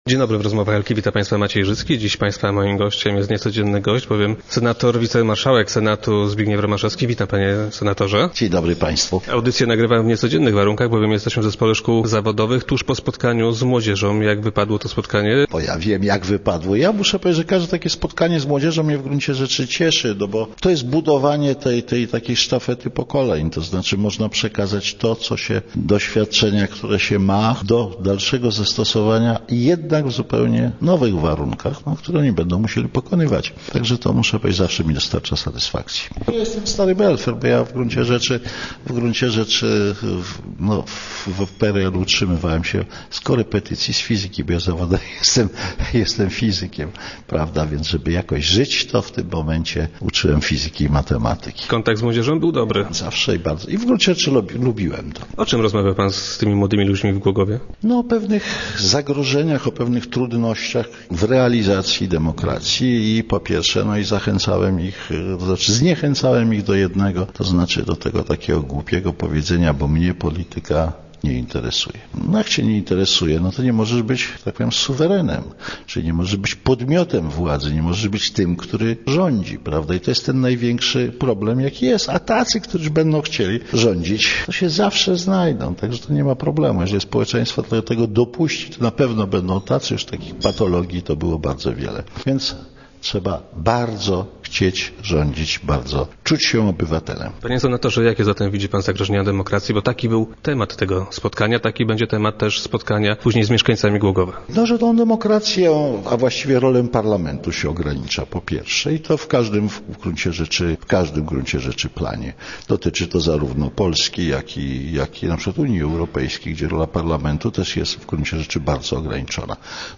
Zdaniem senatora największym zagrożeniem jest ograniczanie roli parlamentu. Dziś marszałek Romaszewski był gościem Rozmów Elki.
Senat mógłby też przejąć pewne funkcje śledcze, nastąpiła bowiem pewna kompromitacja sejmowych komisji śledczych - mówił na radiowej antenie Zbigniew Romaszewski.